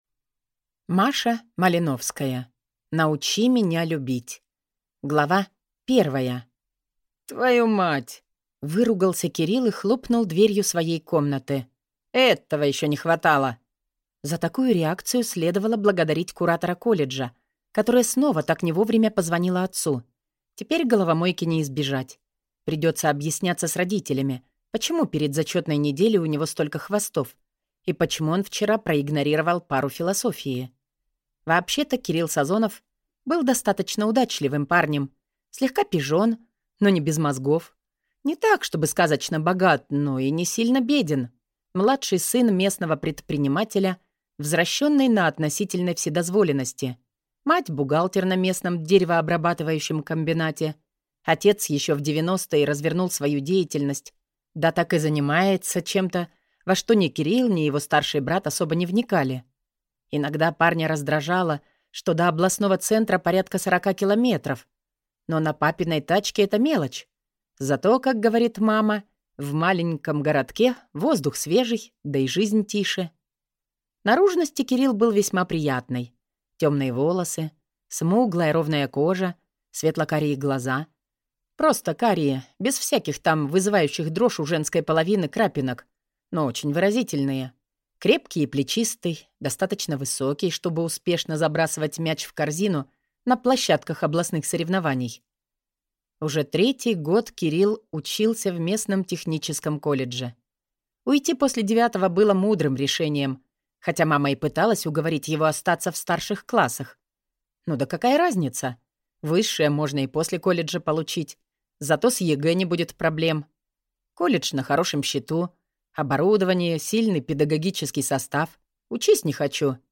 Аудиокнига Научи меня любить | Библиотека аудиокниг